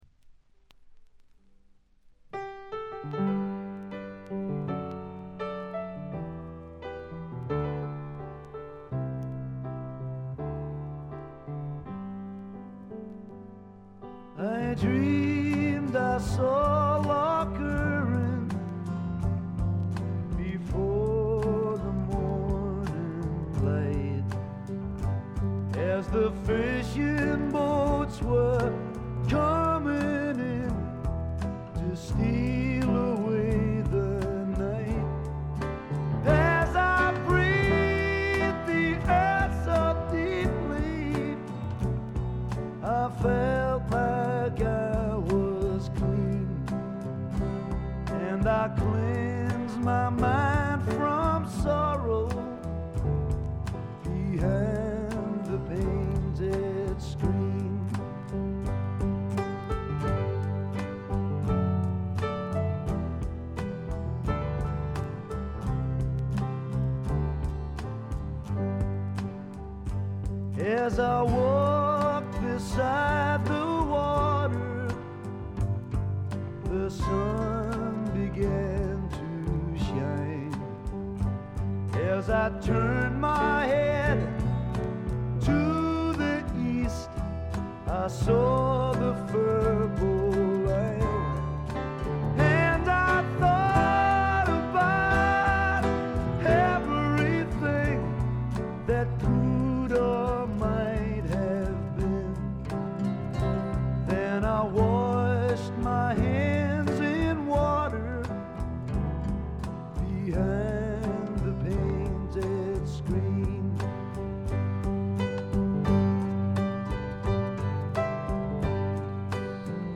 部分試聴ですが、静音部での軽微なバックグラウンドノイズ程度。
なにはともあれ哀愁の英国スワンプ／英国フォークロック基本中の基本です。
試聴曲は現品からの取り込み音源です。